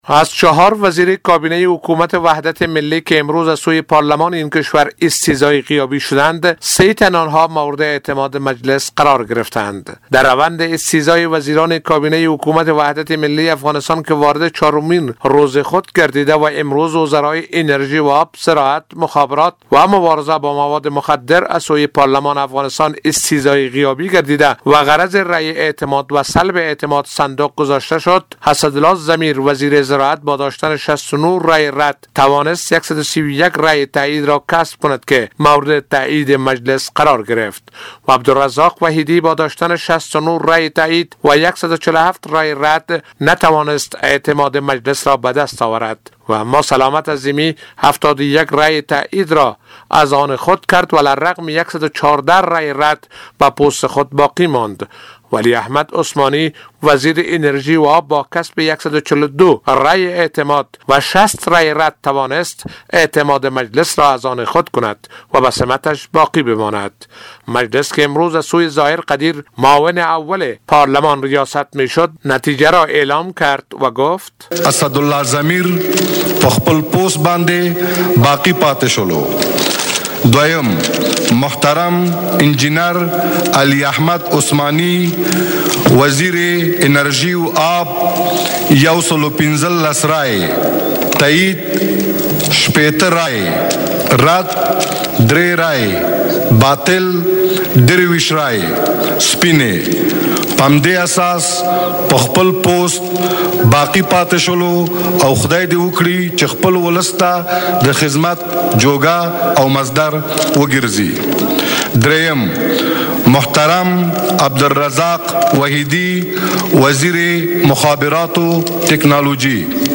گزارش؛ پارلمان افغانستان به سه وزیر کابینه اشرف غنی اعتماد کرد